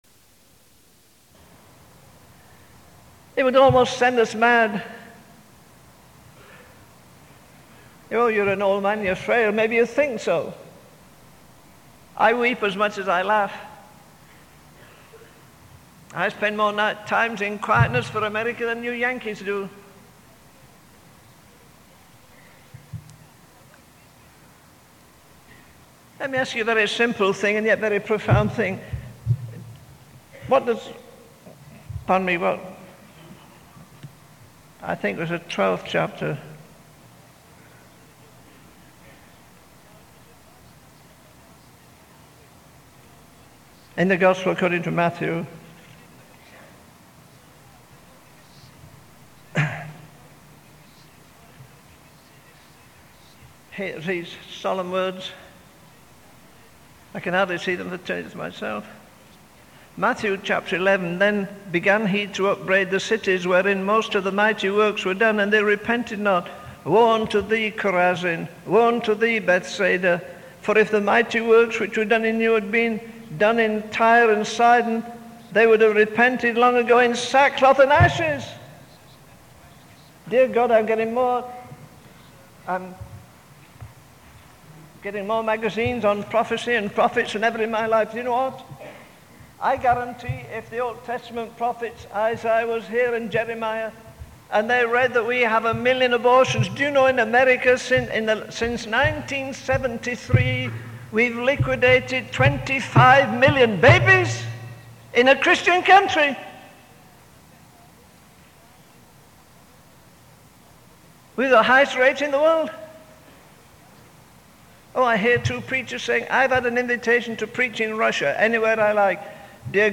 In this sermon, the speaker expresses his deep concern for the spiritual state of America and England. He references Matthew 11:20-21, where Jesus rebukes the cities of Khorazin and Bethsaida for not repenting despite witnessing mighty works. The speaker laments the lack of spiritual fervor in society, comparing it to the days of Noah.